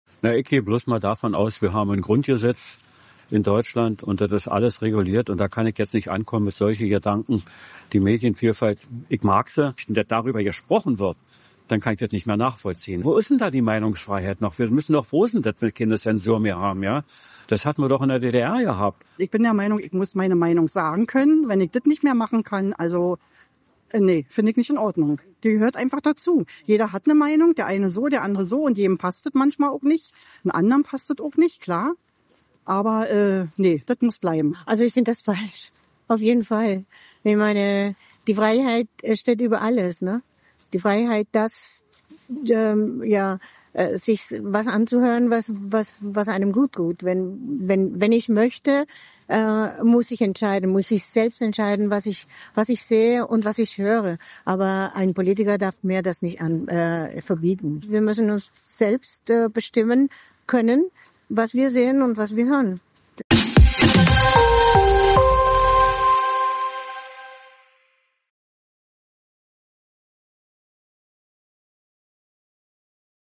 Berliner zu Günthers Zensur-Phantasien: „Das hatten wir in der DDR“
hat sich in Berlin umgehört.